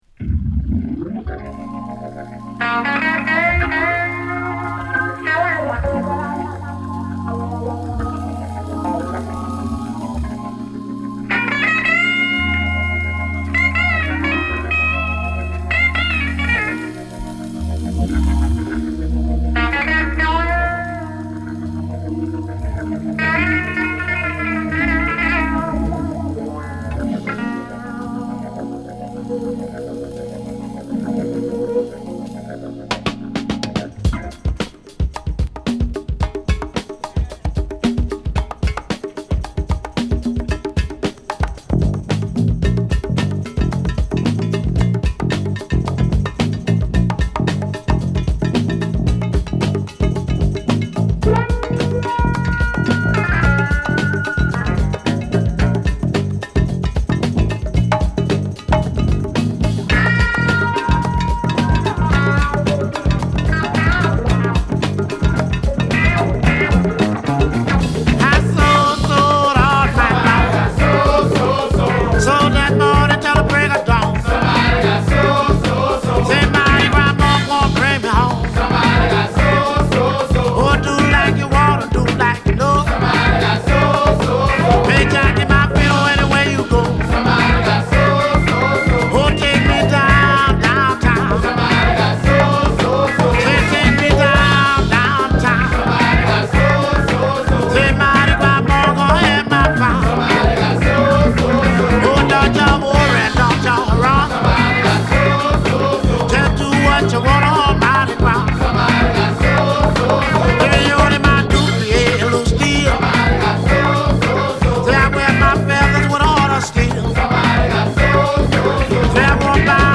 南部の香りが漂いつつもサザンソウルにはならない独特のファンキーサウンドを披露。